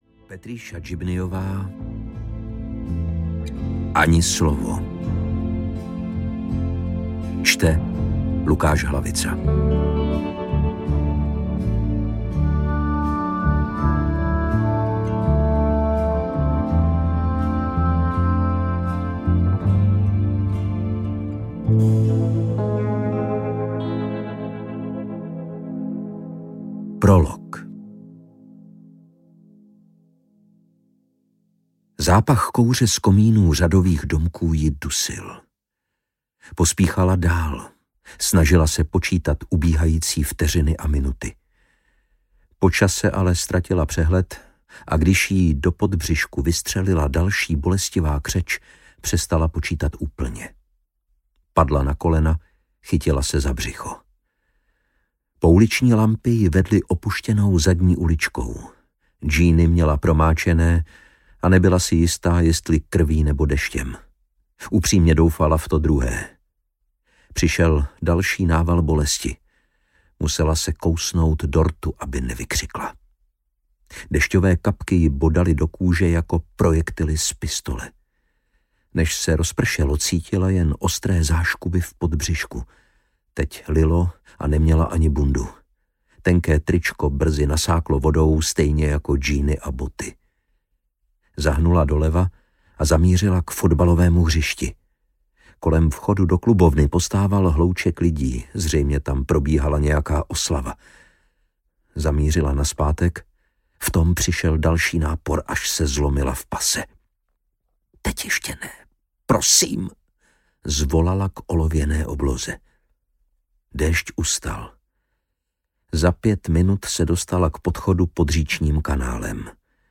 Ani slovo audiokniha
Ukázka z knihy
• InterpretLukáš Hlavica